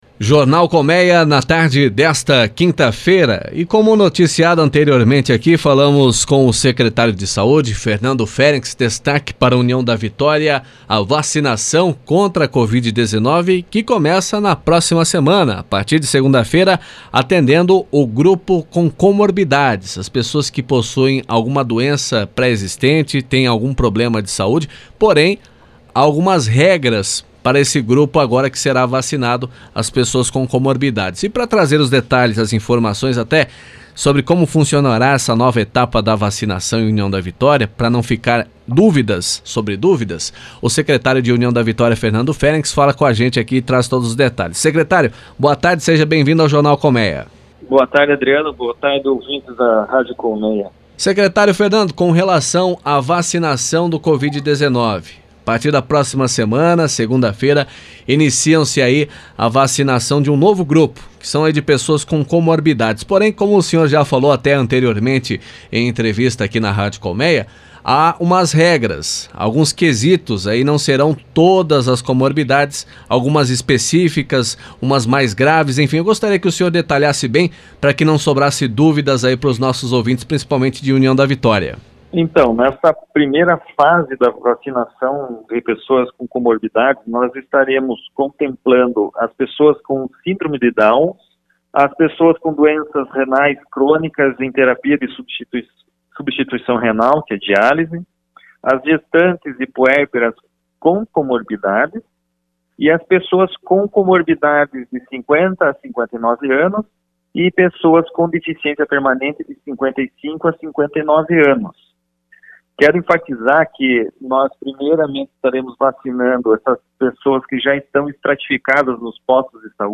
ENTREVISTA-SEC-SAUDE-FERNANDO-FERENCZ.mp3